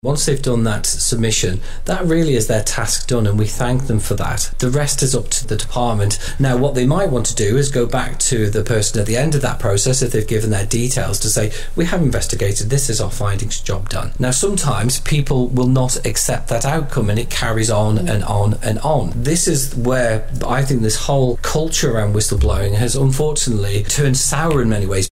Rob Callister MHK has been giving evidence to Tynwald committee